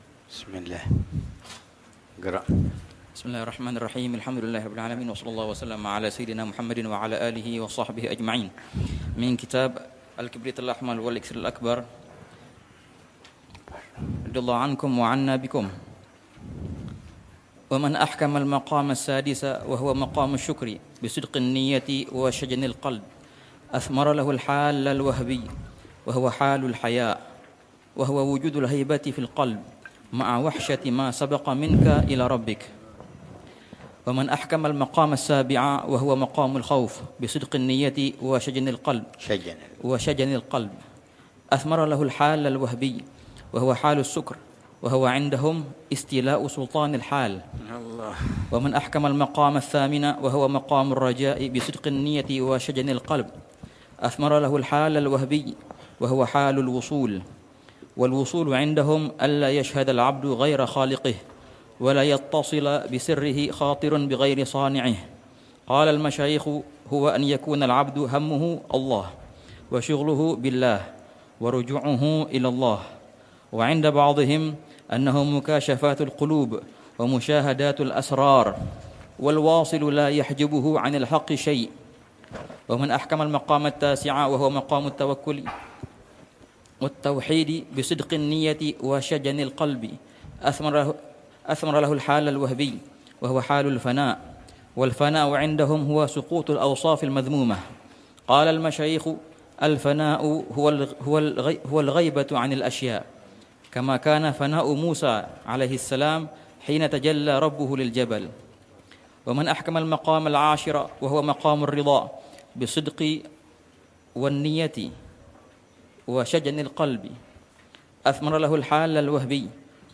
الدرس السابع للعلامة الحبيب عمر بن محمد بن حفيظ في شرح كتاب: الكبريت الأحمر و الأكسير الأكبر في معرفة أسرار السلوك إلى ملك الملوك ، للإمام